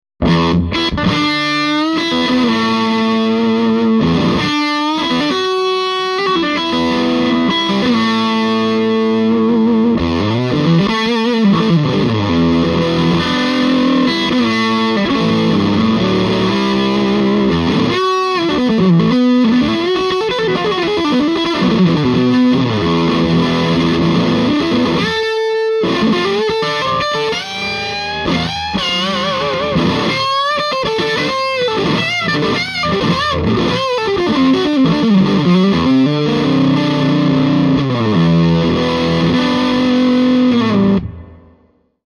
With the EL-34s, the SST-30 takes on a more British tone without losing the richness of the midrange.
Gibson SG-High Gain   :42